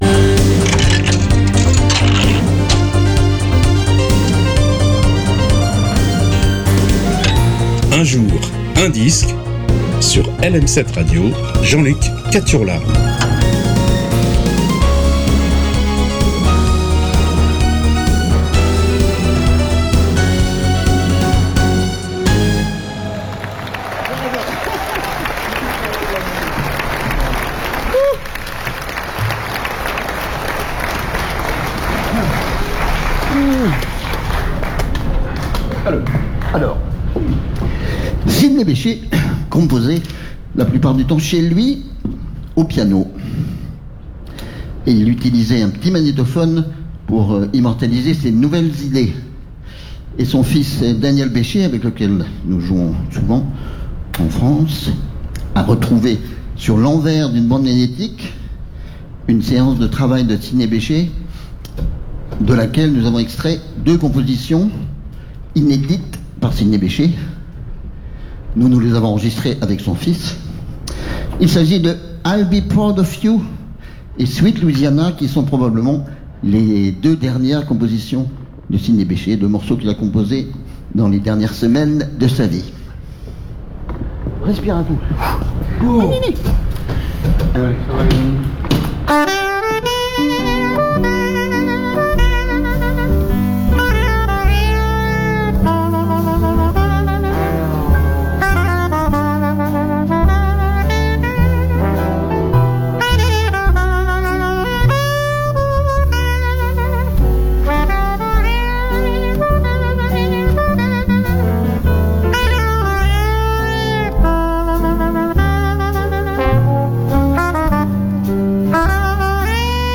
rythmes jazzy